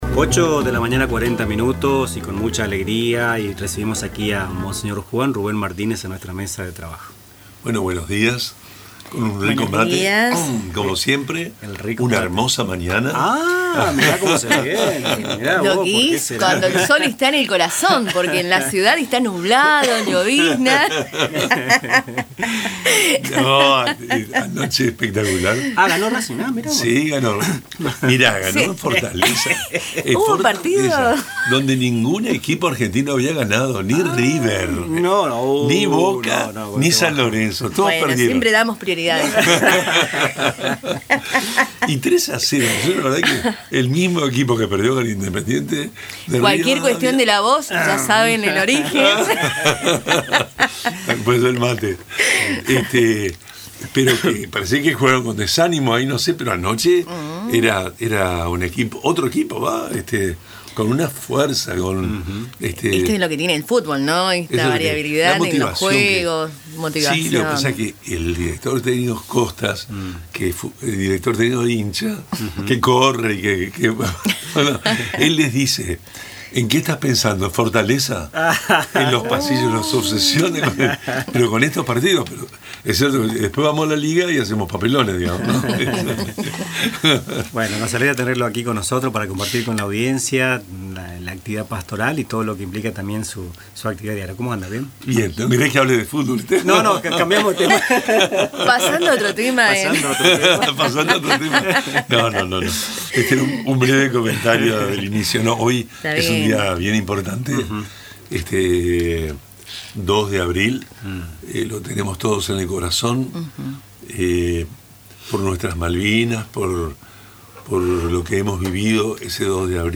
En su tradicional ciclo radial en Radio Tupa Mbae, el obispo de la diócesis de Posadas, monseñor Juan Rubén Martínez, reflexionó sobre el Día del Veterano y de los Caídos en la Guerra de Malvinas, resaltando su significado como una jornada de unidad nacional y memoria histórica.